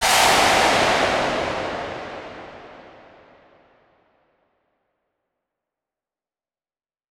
Touhou Crash.wav